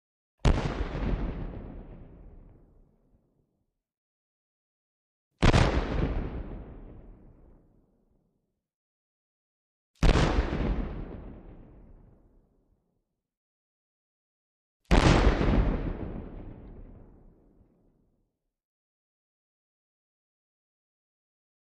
Distant Single Cannon Fires ( 4x ); Four Separate Cannon Fires. Loud, Deep, Boomy Cannon Fires With Long Echo. Medium Distant Perspective.